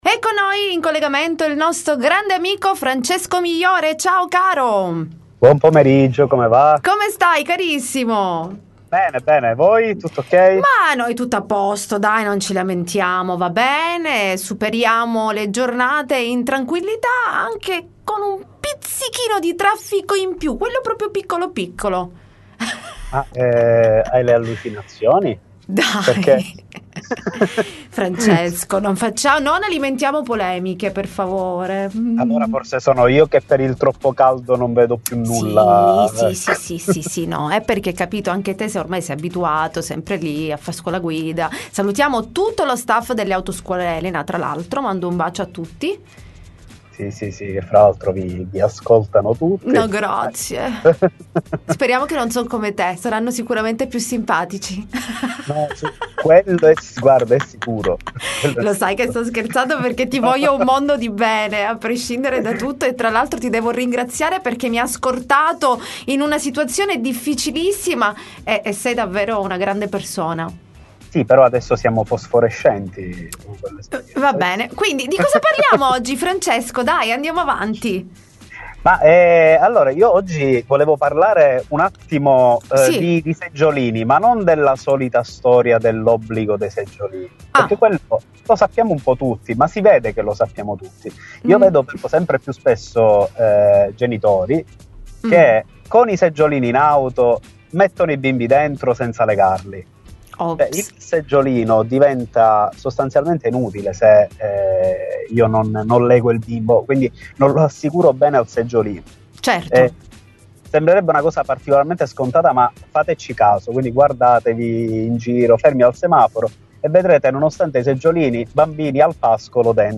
Life Time intervista Autoscuola Migliore